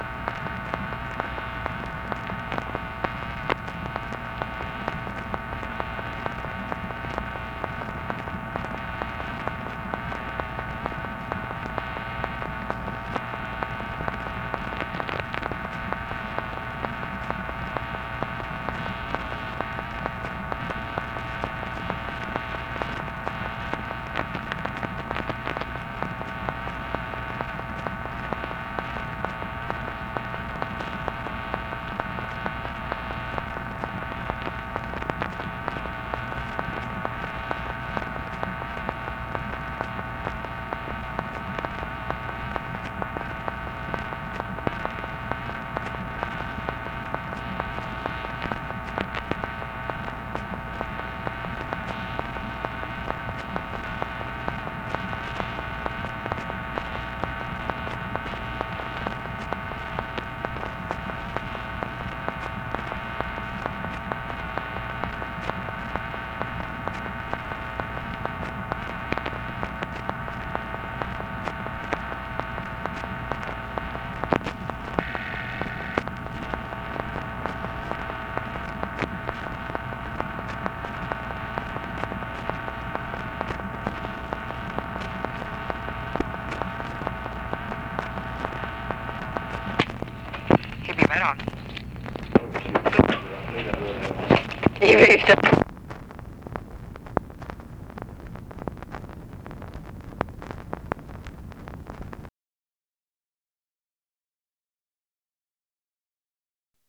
PRIMARILY MACHINE NOISE; UNIDENTIFIED FEMALE SAYS "HE'LL BE RIGHT ON"
Conversation with MACHINE NOISE
Secret White House Tapes